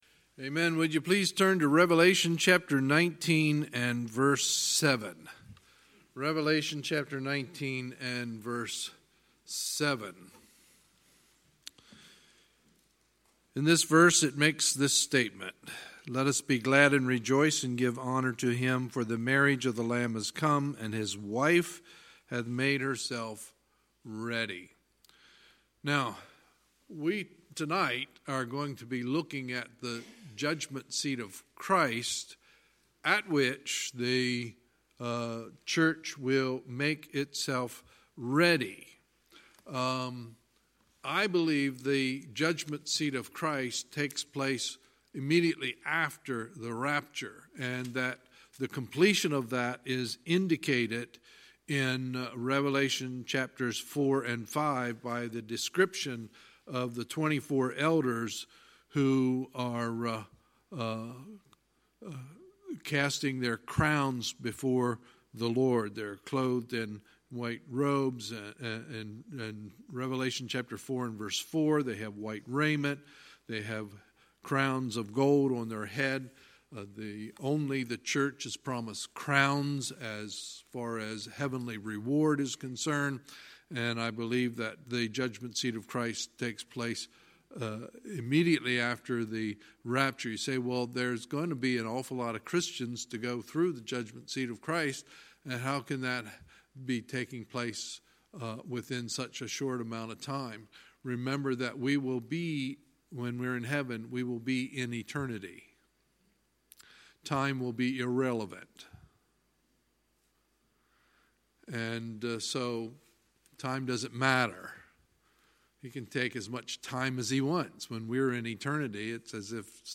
Sunday, June 30, 2019 – Sunday Evening Service